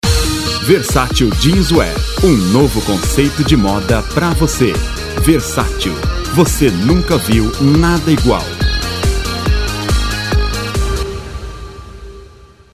Masculino
Voz Jovem